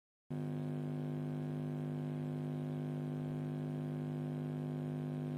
Marshall Valvestate 100V Head - Gain u. Volumen unabhängiges Brummen
Ich habe jedoch ein permanentes Brummen.
Das Top ist an einen Marshall 1960A angeschlossen.